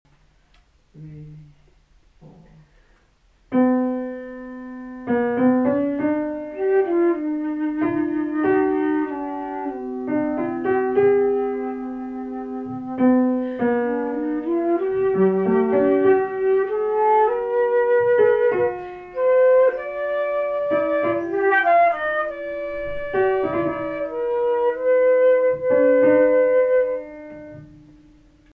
The upper voice plays a variation of the Royal theme, and the two lower voices play a counterpoint in canon.
The two voices go in opposite directions.
The result is that the leader is played twice, during the same time that the follower is played once.
canon4flutepiano.mp3